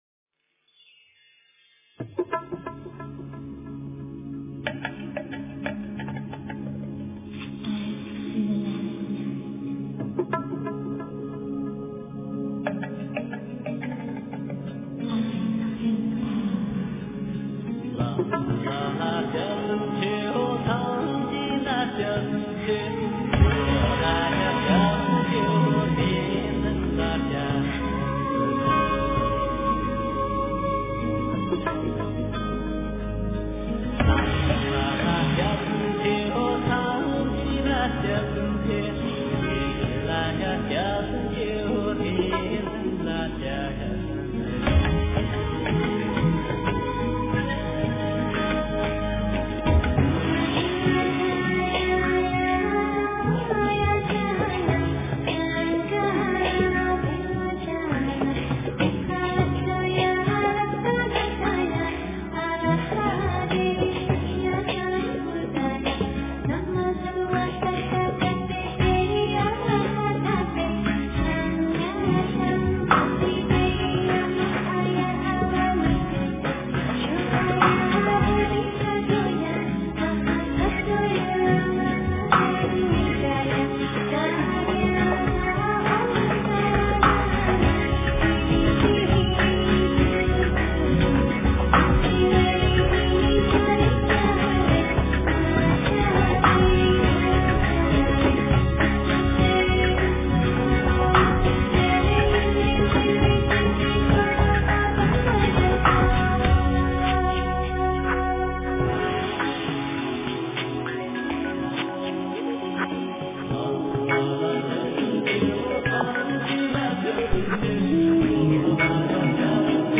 标签: 佛音真言佛教音乐